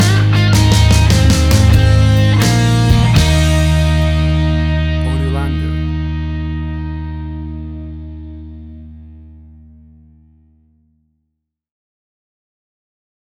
A big and powerful rocking version
Tempo (BPM): 110